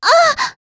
s027_Impact_Hit.wav